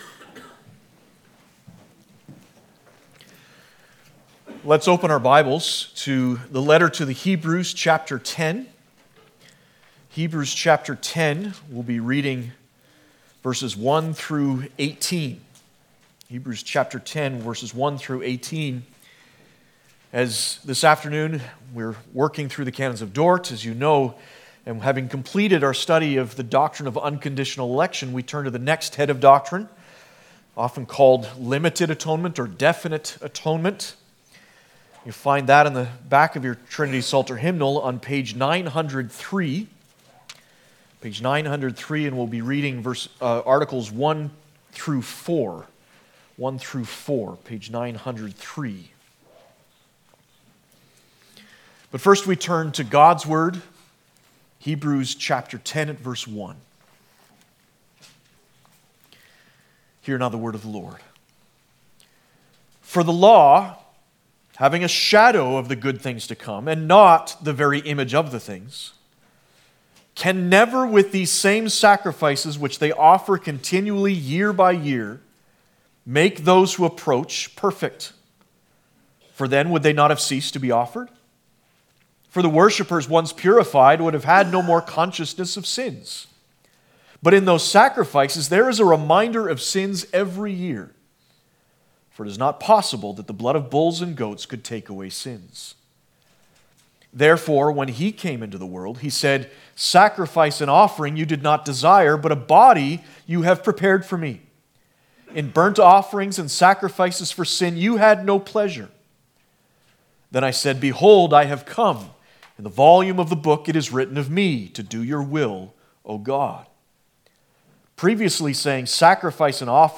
Passage: Heb 10:1-18, Canons of Dordt II 1-4 Service Type: Sunday Afternoon